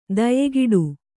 ♪ dayegiḍu